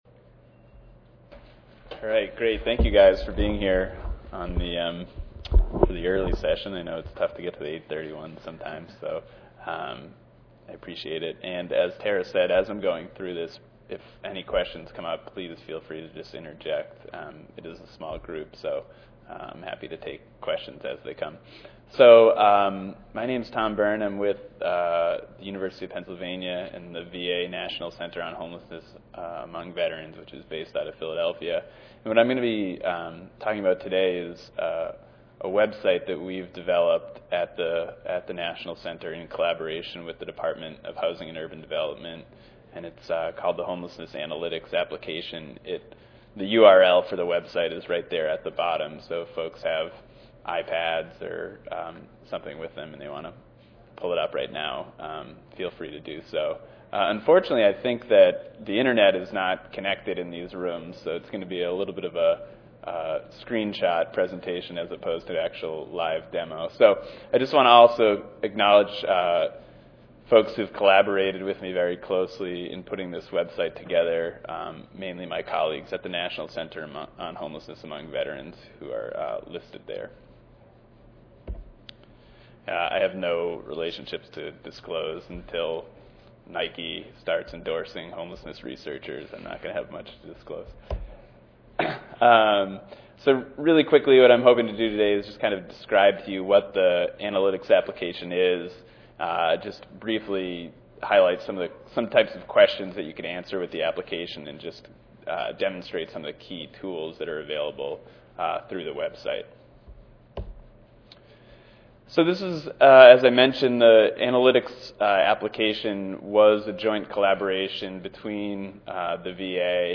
141st APHA Annual Meeting and Exposition (November 2 - November 6, 2013): Homelessness analytics initiative—a web-based community planning tool for preventing and ending homelessness